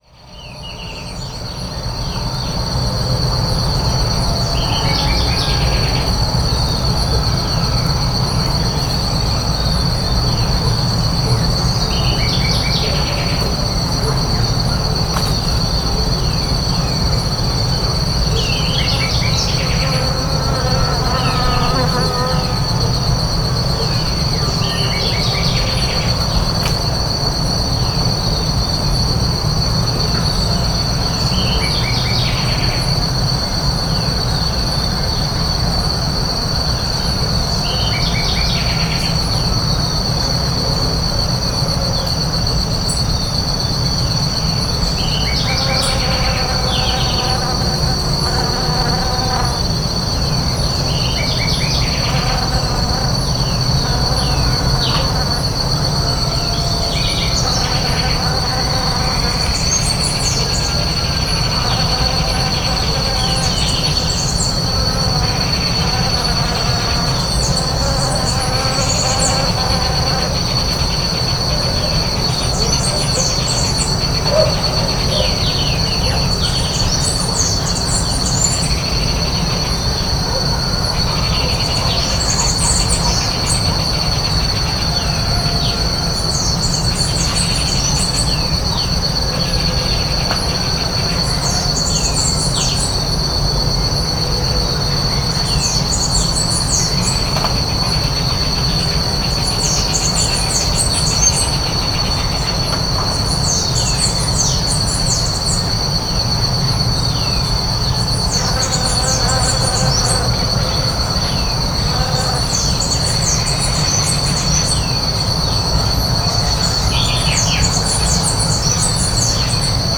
When I record for long periods of time on crowded forests in the nighttime, quickly I start to feel inpatient; I remain as still as possible not to interfere with the incidental sounds and for the same reason I don’t turn on any light so it is usually very quiet and dark out there.
Sounds were captured in peripheral areas of the country side whose vegetation and fauna resembles that of the jungle and tropical forest.
Field Recording Series by Gruenrekorder